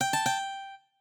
lute_gag.ogg